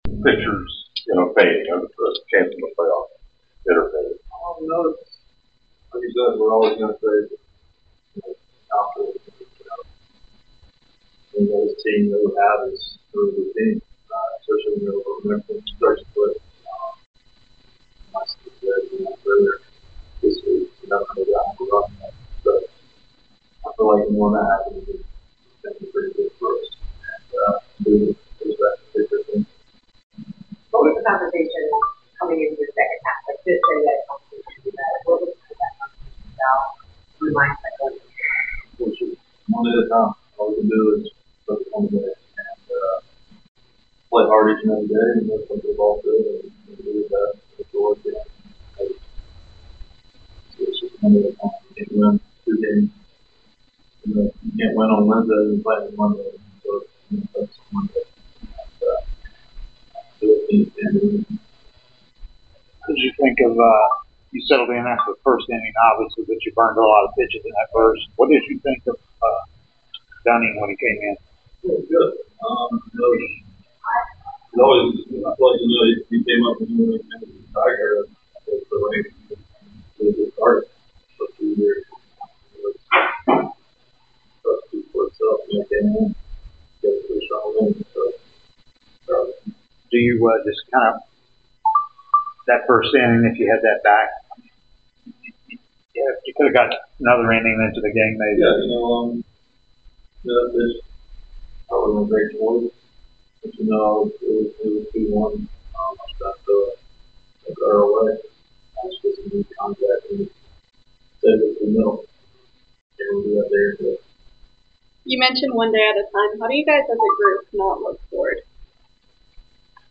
Atlanta Braves Pitcher Grant Holmes Postgame Interview after losing to the New York Yankees at Truist Park.